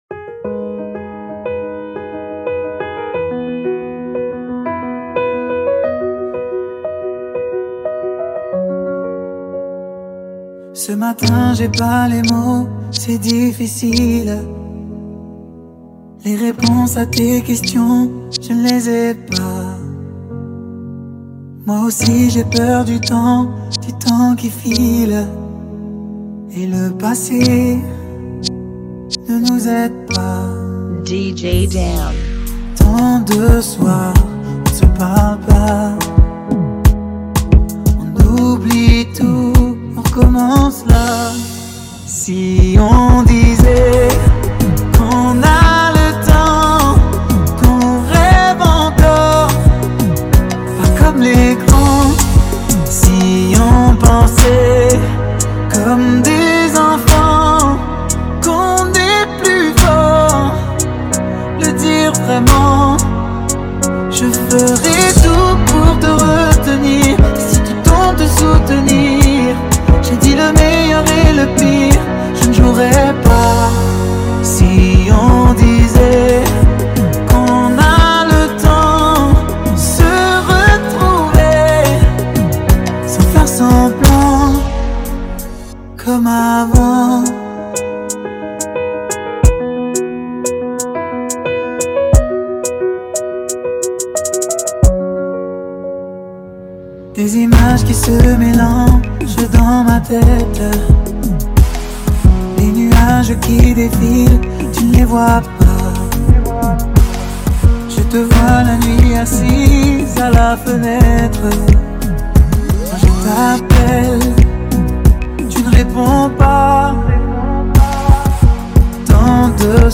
Genre: Kizomba Remix